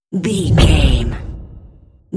Dramatic hit deep fast trailer
Sound Effects
Atonal
Fast
heavy
intense
dark
aggressive